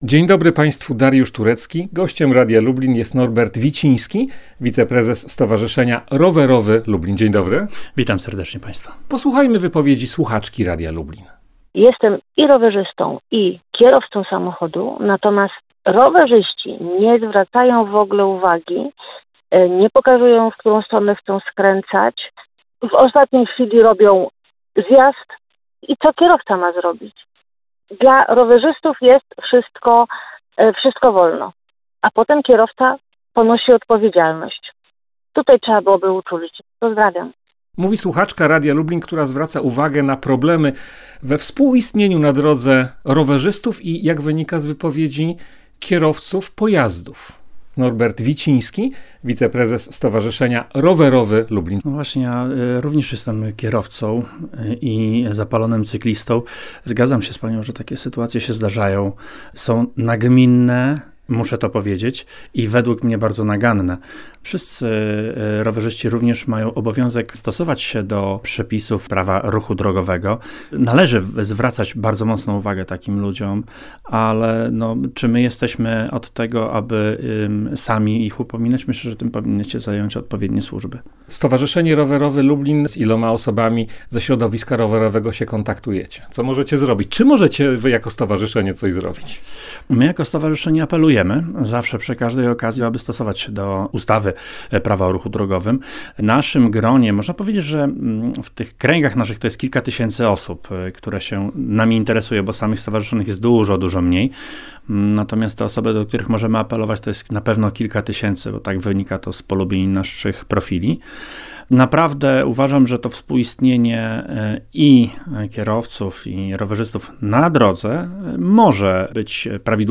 – Rowerzyści nie pokazują, w którą stronę chcą skręcać, a potem kierowca ponosi odpowiedzialność – zauważa słuchaczka Radia Lublin, która deklaruje się zarówno jako rowerzystka, jak i prowadząca samochód. Co na te uwagi przedstawiciel środowiska rowerowego?